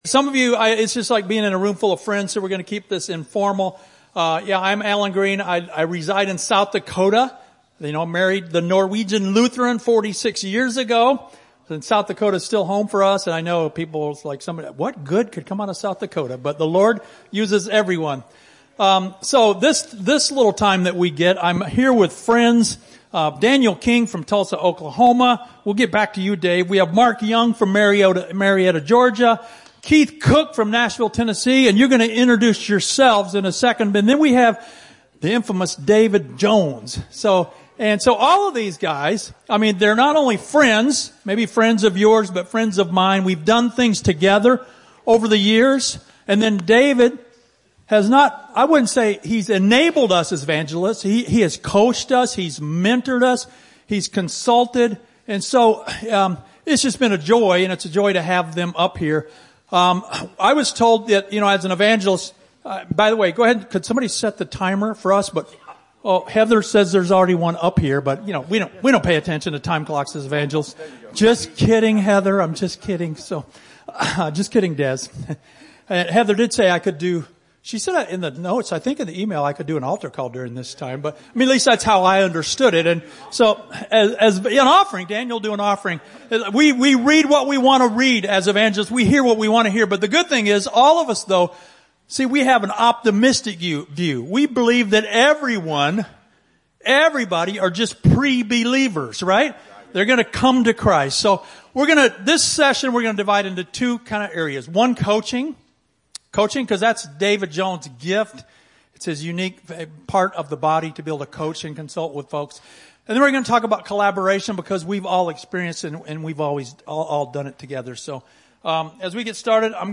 GNE Connect July 2022 - Panel Discussion 1
GNE-panel-discussion.mp3